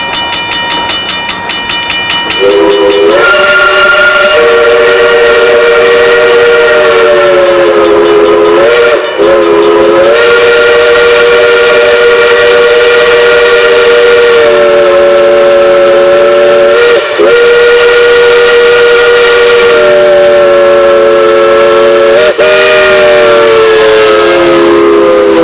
whistling for Everitts Road grade crossing